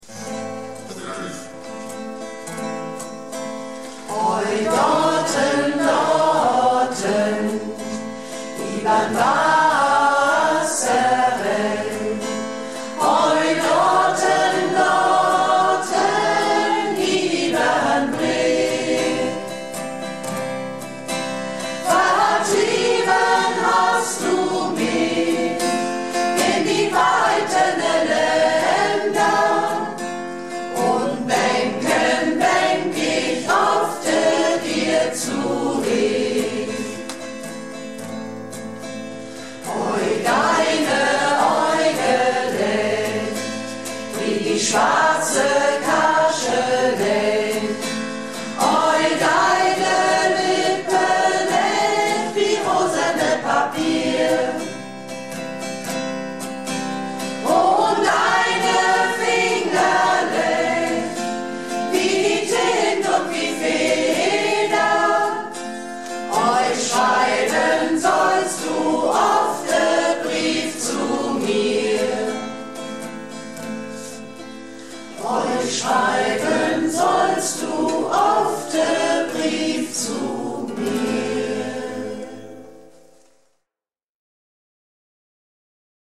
Recha Freier-Projektchor - Probe am 17.01.17